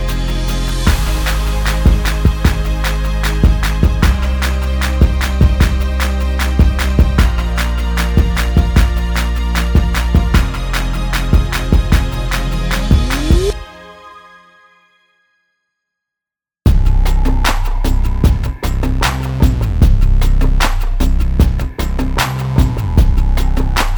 no Backing Vocals Pop (2010s) 3:22 Buy £1.50